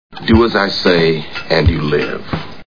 Snakes on a Plane Movie Sound Bites